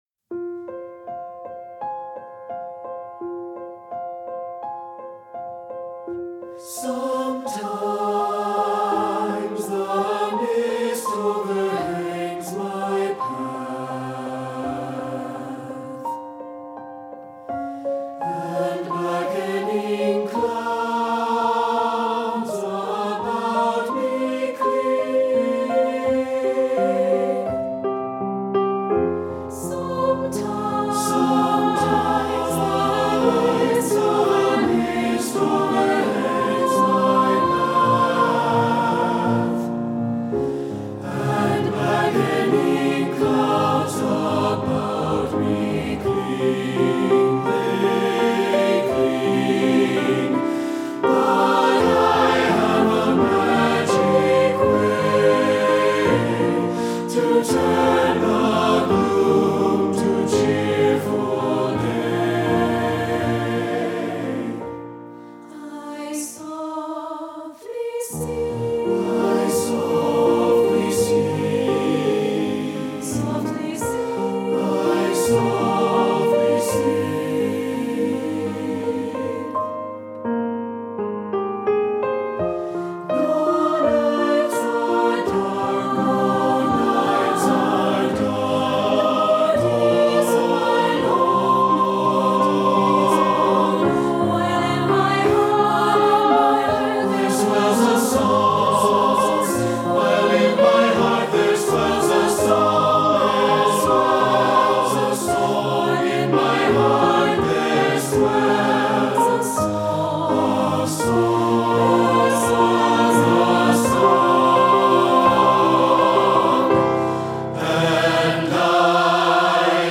Choral Concert/General
is gentle and graceful
SATB Audio